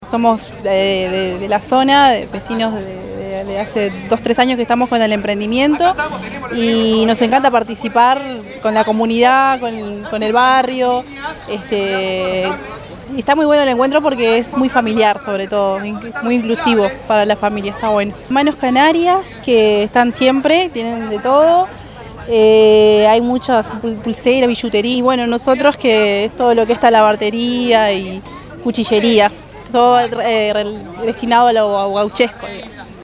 A orillas del arroyo Pando en El Pinar, Ciudad de la Costa celebró sus 29 años con la colocación de un escenario en el que hubo artistas locales, nacionales, una feria de emprendedores y diversas autoridades.